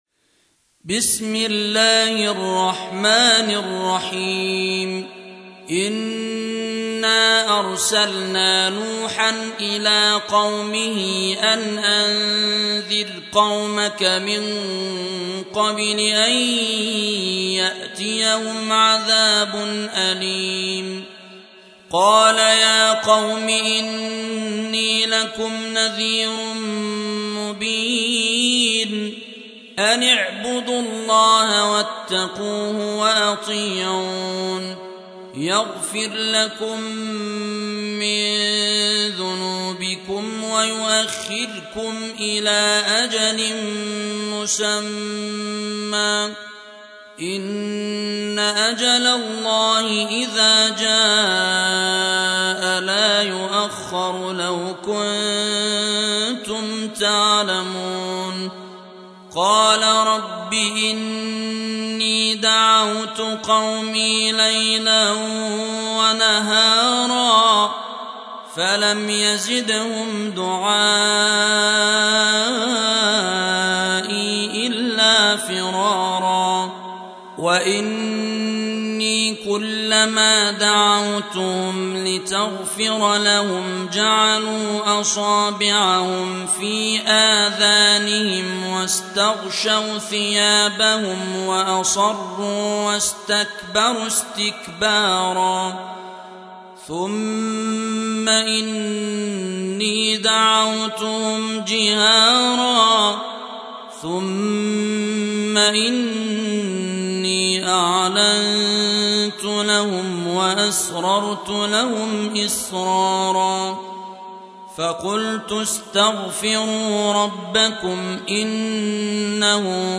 Audio Quran Tarteel Recitation
حفص عن عاصم Hafs for Assem
Surah Repeating تكرار السورة Download Surah حمّل السورة Reciting Murattalah Audio for 71. Surah N�h سورة نوح N.B *Surah Includes Al-Basmalah Reciters Sequents تتابع التلاوات Reciters Repeats تكرار التلاوات